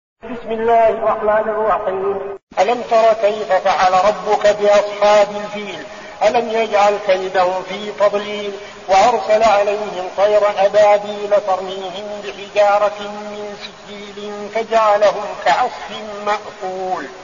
المكان: المسجد النبوي الشيخ: فضيلة الشيخ عبدالعزيز بن صالح فضيلة الشيخ عبدالعزيز بن صالح الفيل